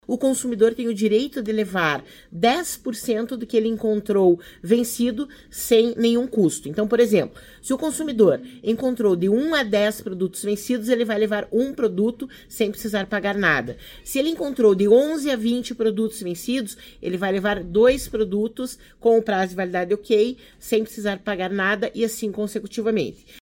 A coordenadora do Procon-PR, Cláudia Silvano, destaca a importância de pedir nota fiscal e também orienta o que fazer ao encontrar um produto fora do prazo de validade.